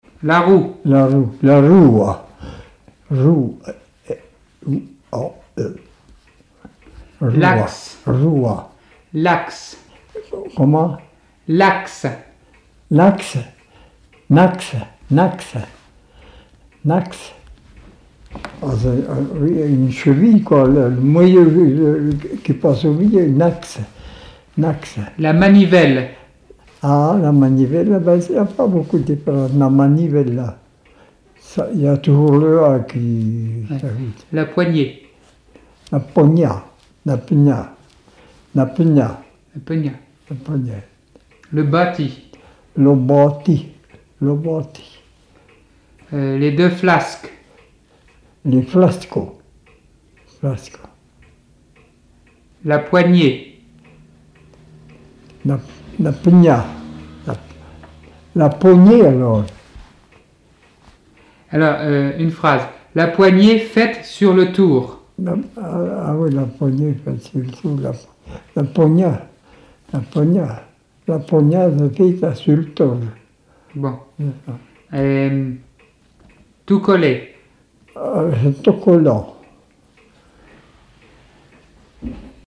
Appels d'animaux, locution vernaculaires, chansons et témoignages
Catégorie Locution